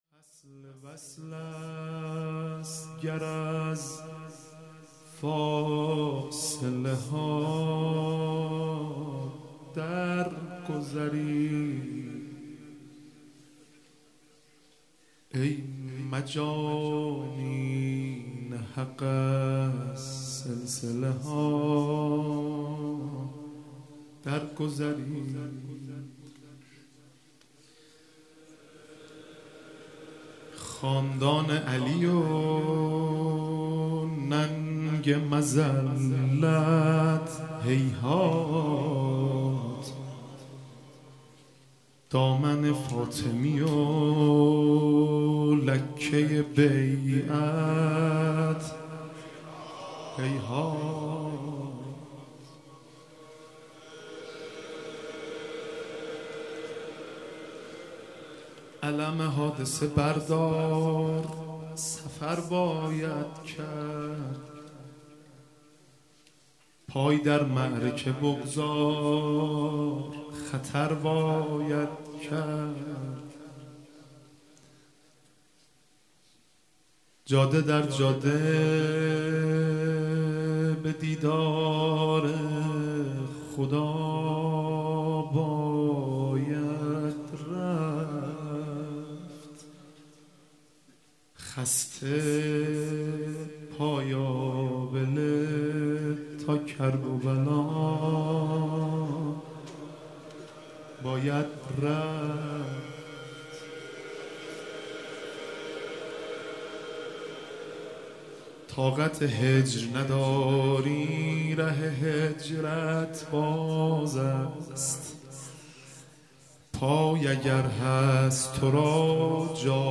مداحی شب اول محرم 1398